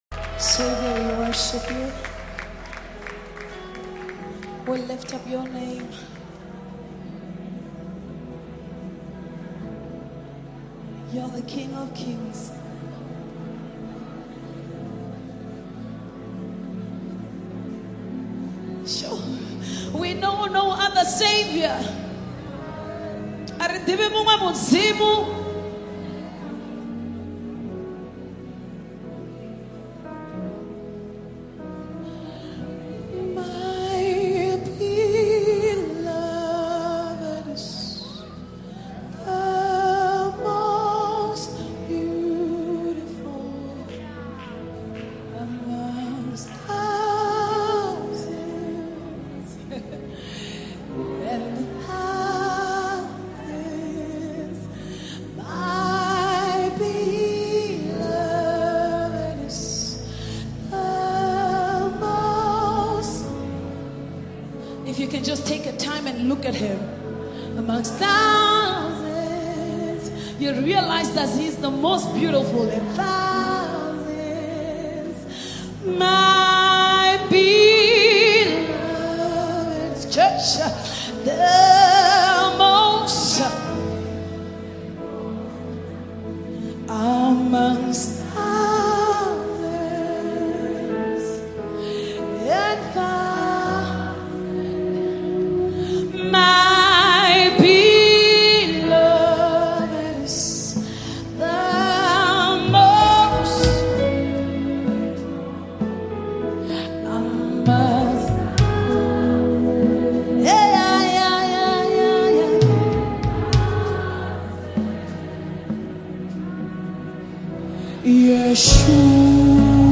anointed vocals, and a captivating melody